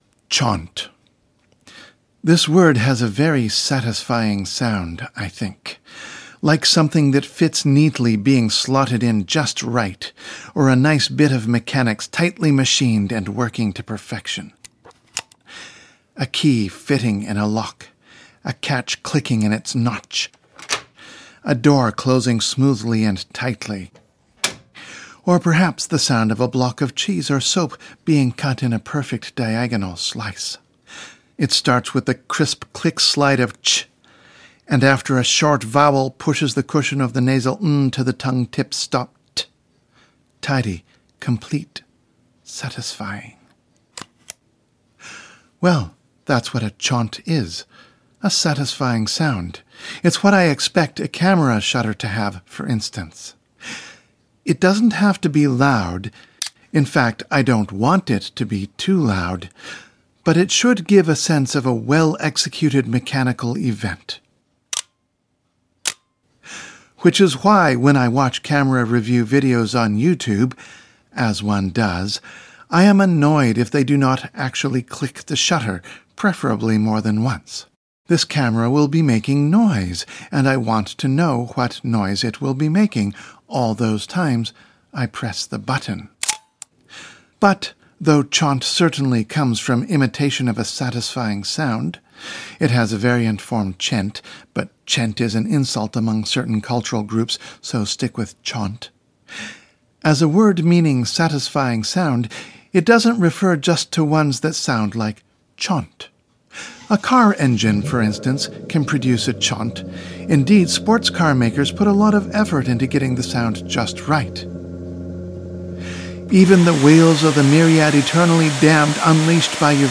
As a bonus, it’s the audio version of a subscribers-only blog post: chont, text version, is available only for those who subscribe for at least $1 per month.
Here is the sound of chont, with lots of chonts.